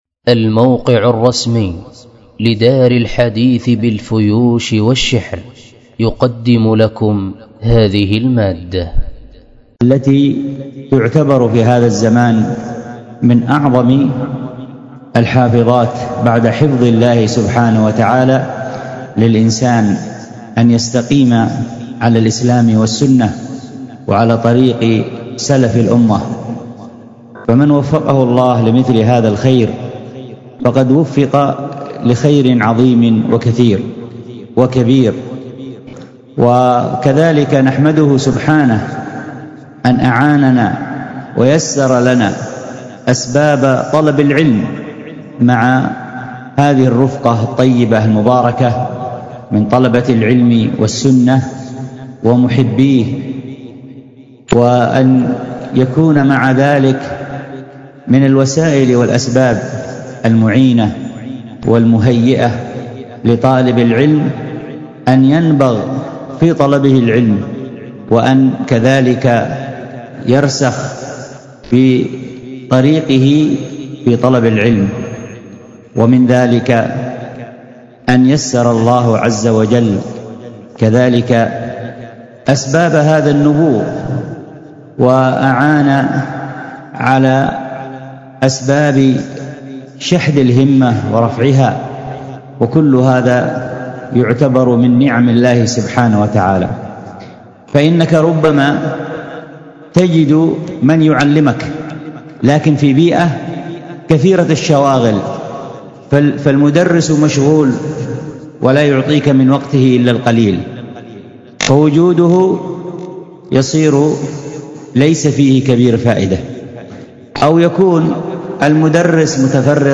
الخطبة بعنوان ذم العجلة، وكانت بمسجد التقوى بدار الحديث بالشحر